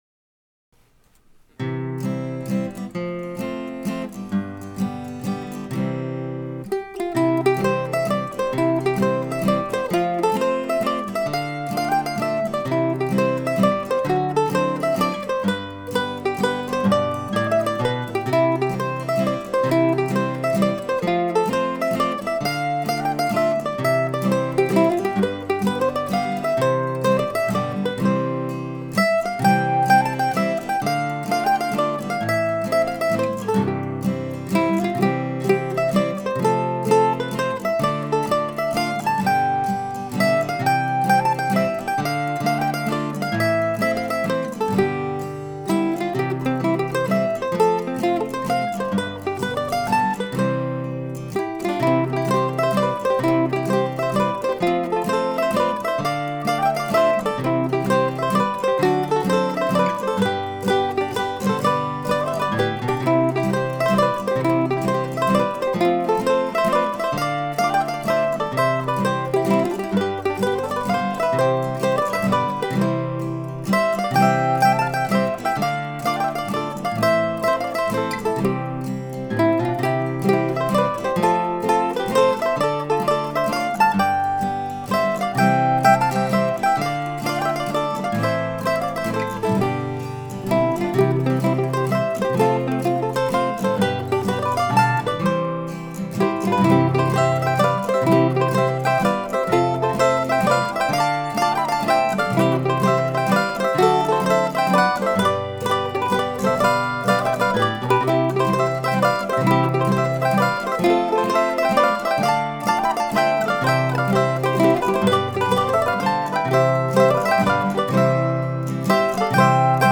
I thought I'd try and write an old-fashioned waltz and this is what came out.
Today's tune is recorded using the A model on the melody part and the new (to me) 1920 F4 playing the two harmony parts.